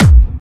VEC3 Clubby Kicks
VEC3 Bassdrums Clubby 020.wav